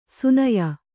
♪ sunaya